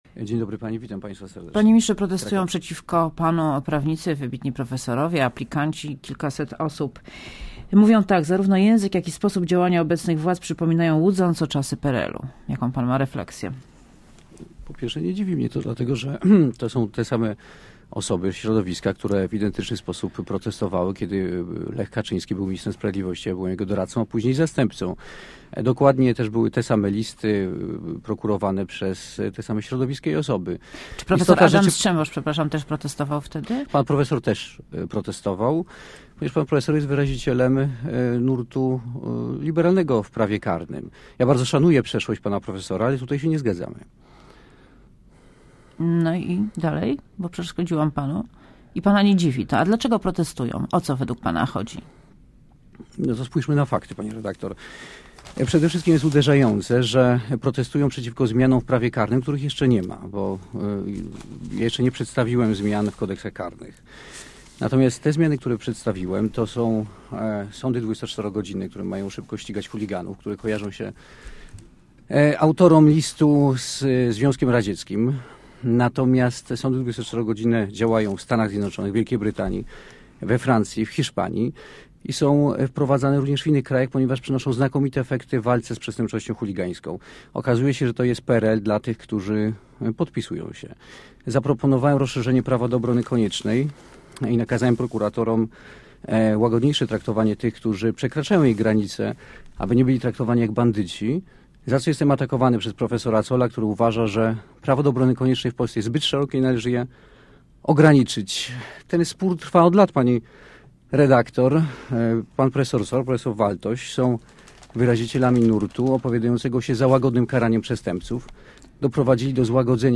Zbigniew Ziobro przypomniał w Radiu Zet, że zaproponował szereg zmian w prawie, które uderzają w różne środowiska.
Posłuchaj wywiadu Zbigniew Ziobro przypomniał w Radiu Zet, że zaproponował szereg zmian w prawie, które uderzają w różne środowiska.